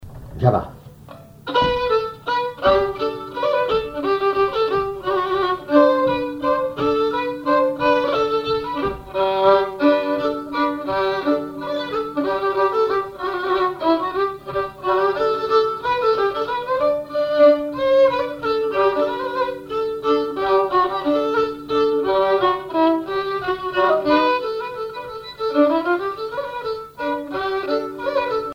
Mémoires et Patrimoines vivants - RaddO est une base de données d'archives iconographiques et sonores.
violoneux, violon,
danse : java
instrumentaux au violon mélange de traditionnel et de variété
Pièce musicale inédite